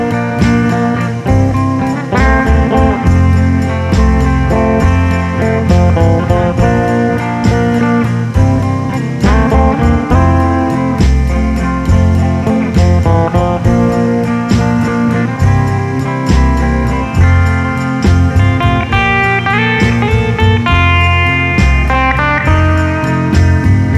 no Backing Vocals Rock 'n' Roll 2:29 Buy £1.50